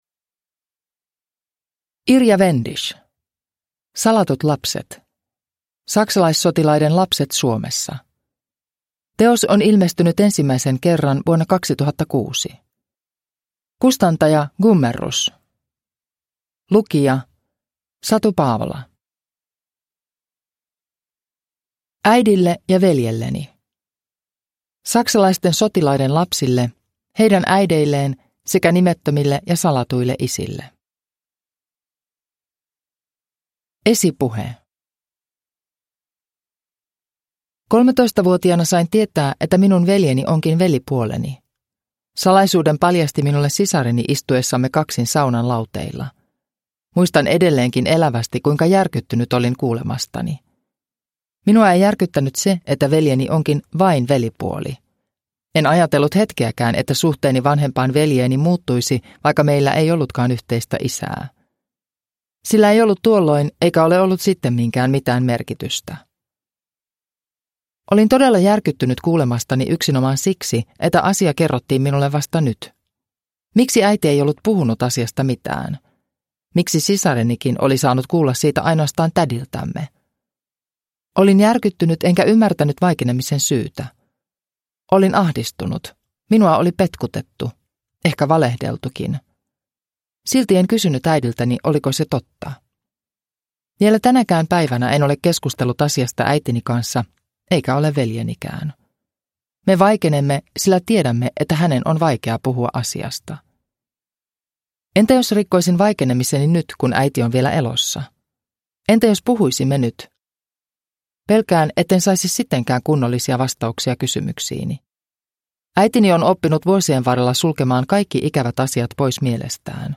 Salatut lapset (ljudbok) av Irja Wendisch